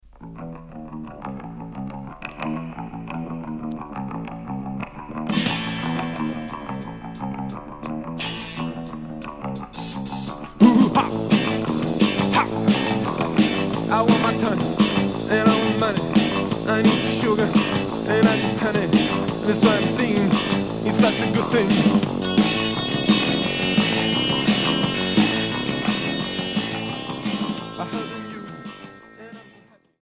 Rock cold wave